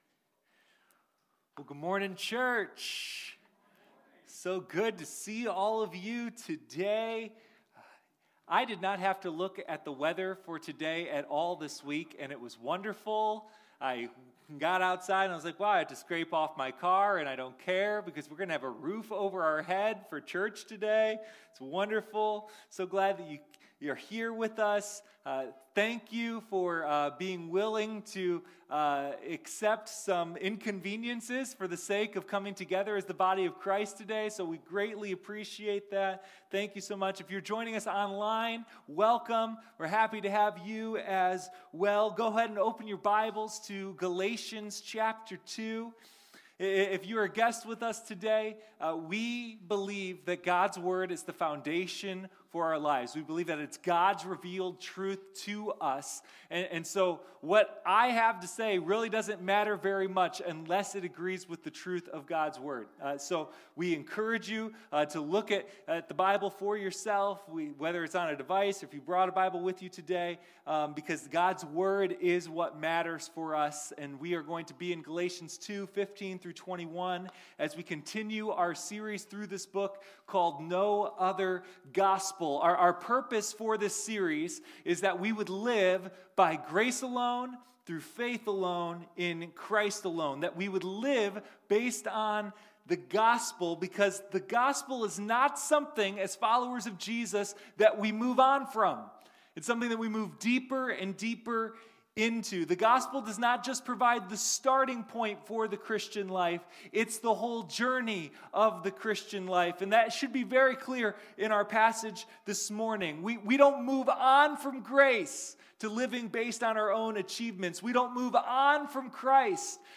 Sunday Morning No Other Gospel: A Study in Galatians